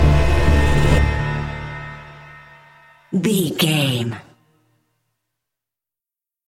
Aeolian/Minor
D
synthesiser
percussion
ominous
dark
suspense
haunting
creepy